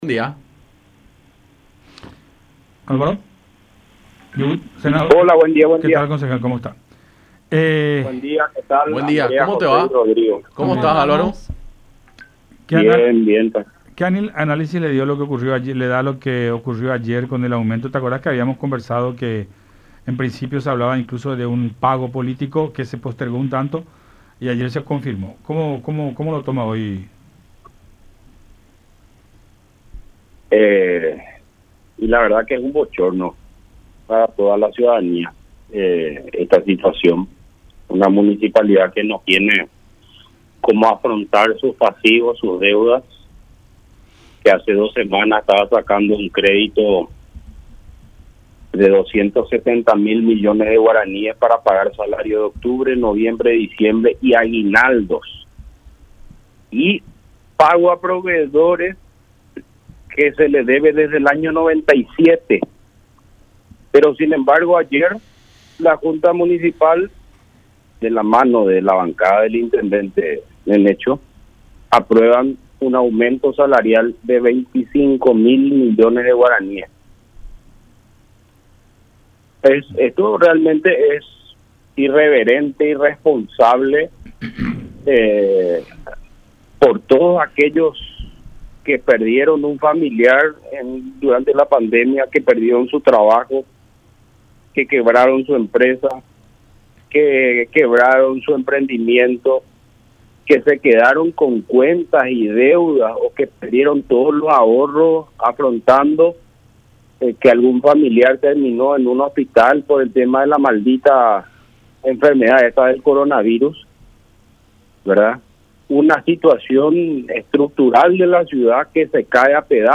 Es un golpe para quienes perdieron a sus familiares en COVID, los que quedaron sin trabajo, los que cerraron y quebraron sus empresas”, manifestó Grau en contacto con Enfoque 800 por La Unión.